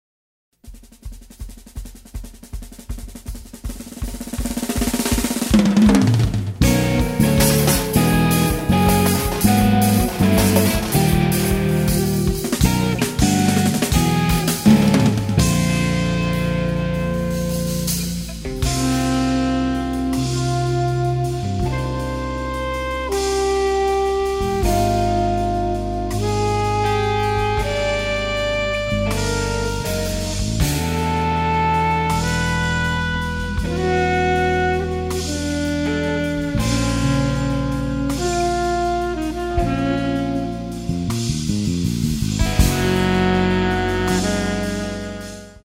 electric bass, keyboards & programming on track 6
guitar on tracks 1, 4, 5 & 8
drums on tracks 1, 4, 5 & 8
alto saxophone on tracks 1, 4, 5 & 8